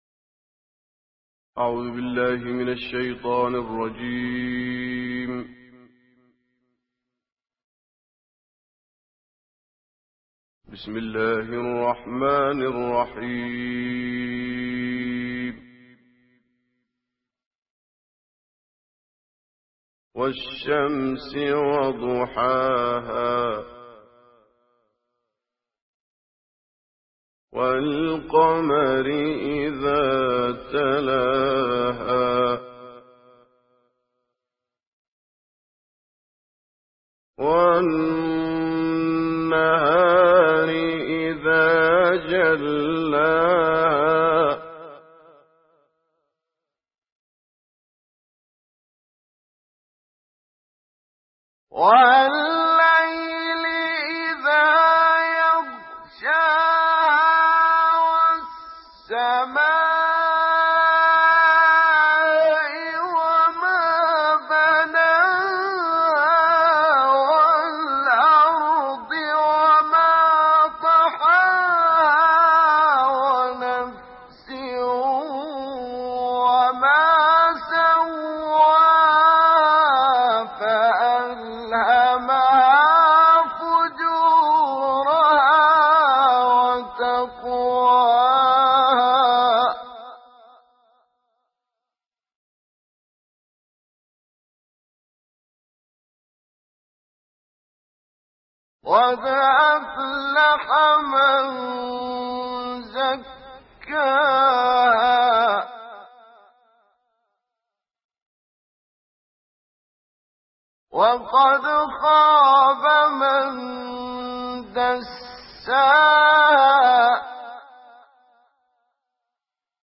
تلاوت مجلسی کوتاه
تلاوت کوتاه مجلسی احمد الرزیقی از آیات 1 تا 14 سوره لیل و سوره شمس به مدت 5 دقیقه 16 ثانیه